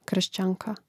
kr̀šćānka kršćanka